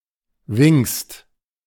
Wingst (German pronunciation: [vɪŋst]